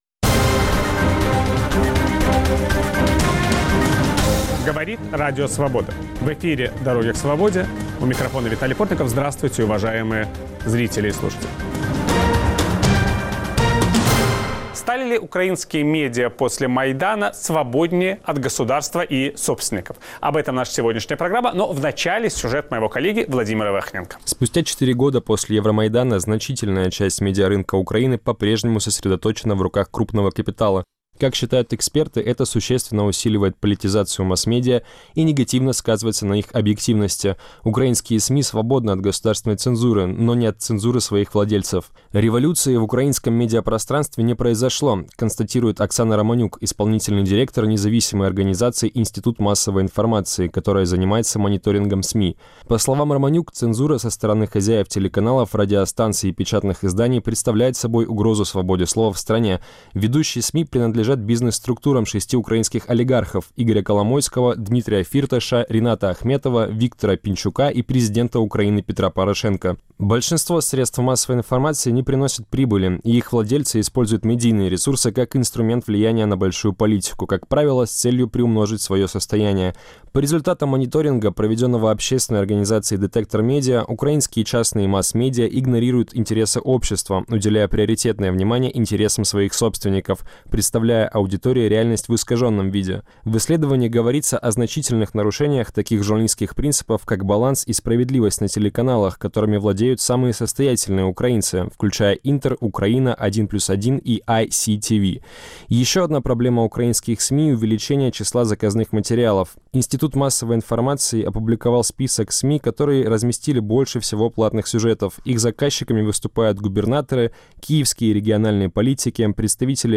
Чьи интересы спустя четыре года после Майдана учитывают украинские медиа - общества или своих владельцев? Виталий Портников беседует с медиа-экспертом